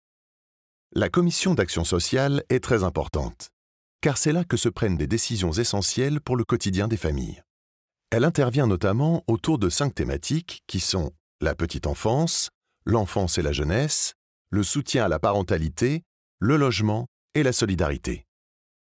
Voici 2 enregistrements : l’un a été généré par une Intelligence Artificielle de pointe, l’autre a été interprété par un comédien professionnel.
Écoutez attentivement les micro-inflexions, le souffle et l’intention…